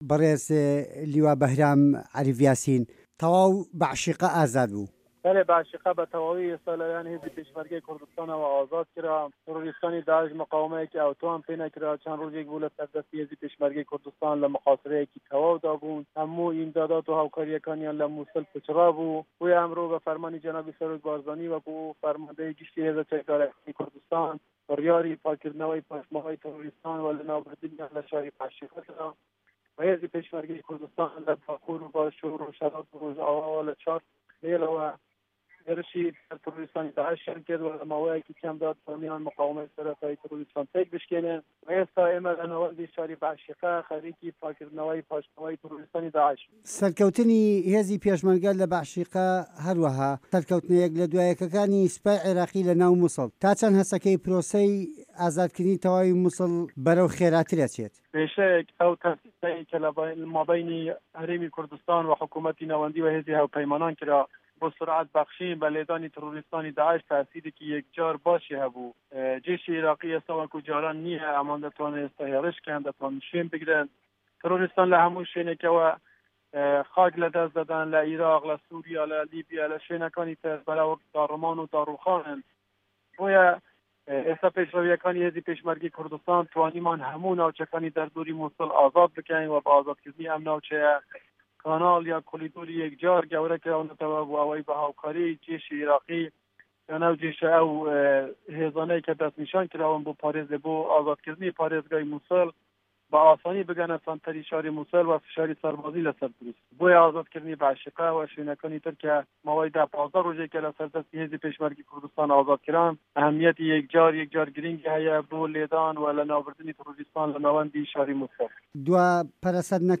وتووێژ لەگەڵ لیوا به‌هرام یاسین